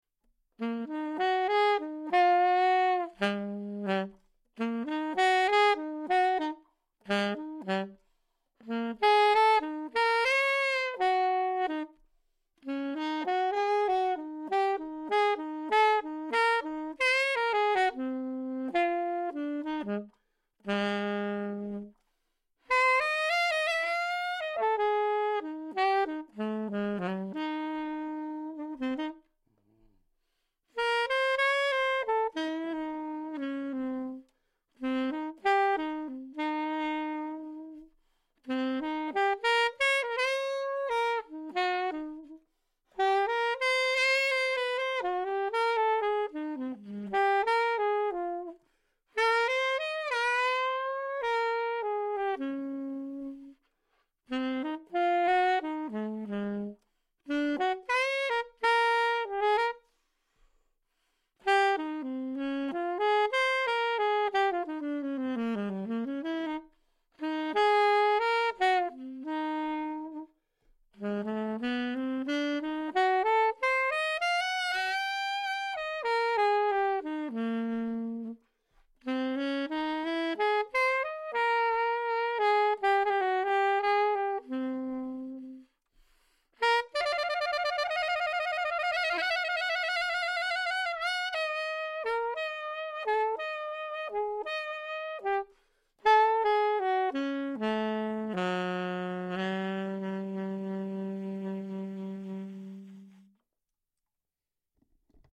a slightly more challenging slow 12/8 blues piece
download mp3 file for Alto Sax...
Blues All Around ALTO.mp3